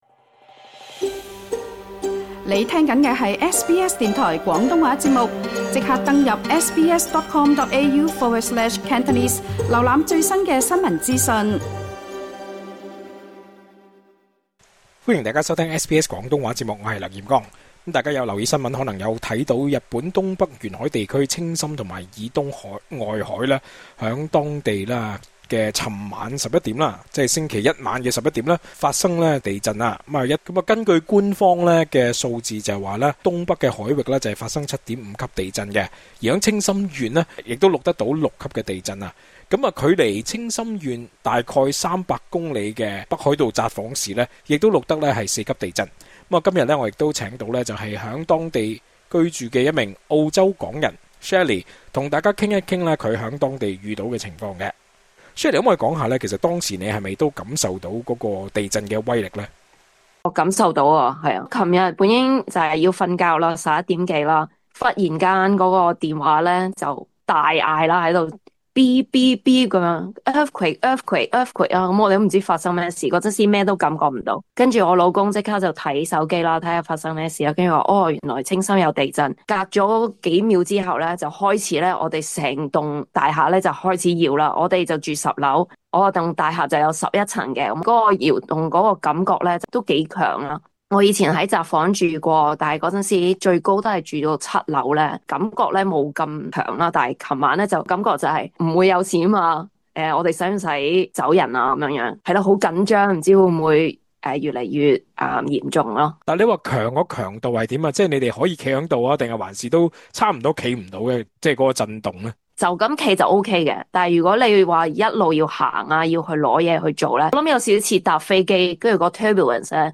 日本東北沿海地區青森縣以東外海在晚上 23:15 左右發生地震，遠至 300 公里外的北海道札幌市亦有震感。居於當地的一名澳籍港人向本台表示，家中明顯搖晃，猶如在飛機上遇到強烈氣流，持續近 5 分鐘。